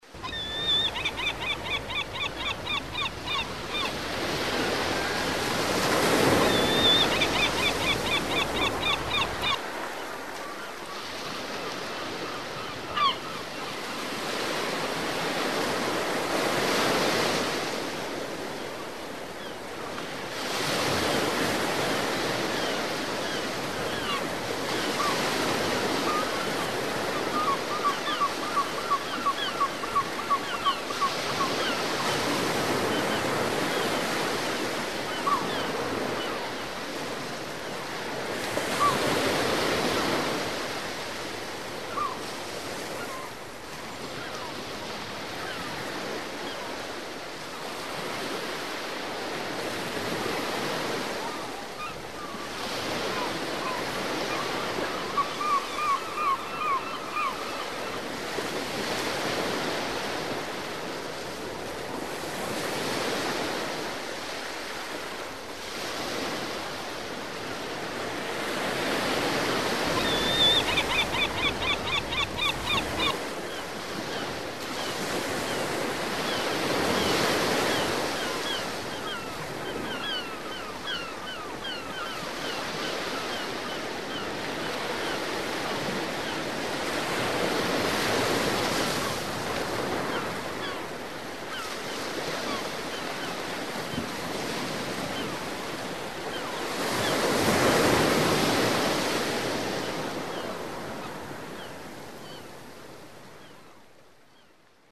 Звуки чаек
Крики этих птиц создают атмосферу побережья, подходят для медитации, звукового оформления и релаксации. В коллекции – разные варианты голосов чаек, от одиночных до шумных стай.